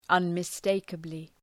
Προφορά
{,ʌnmı’steıkəblı}